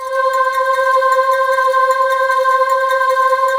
Index of /90_sSampleCDs/USB Soundscan vol.28 - Choir Acoustic & Synth [AKAI] 1CD/Partition C/12-LIVES